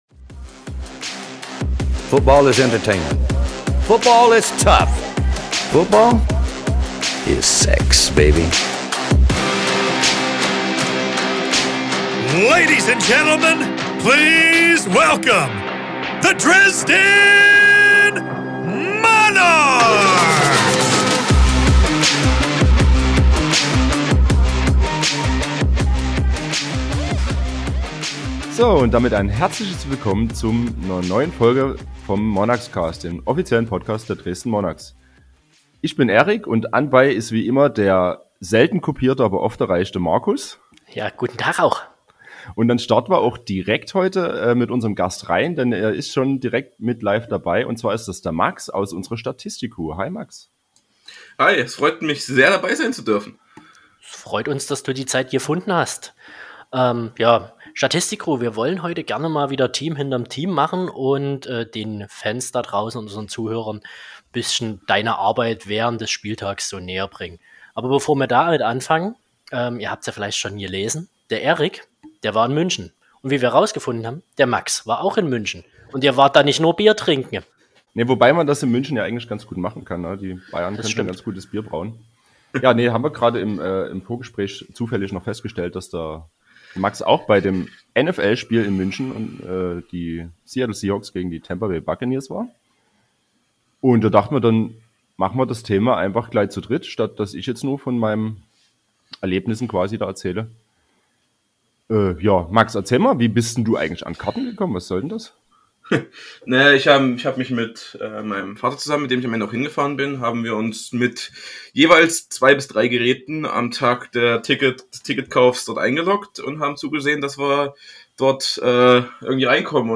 Wir informieren euch in der GFL freien Zeit einmal monatlich mit einer Folge, in welcher wir euch über aktuelle News auf dem Laufenden halten. Außerdem wird immer ein interessanter Gast in einem Interview Rede und Antwort stehen.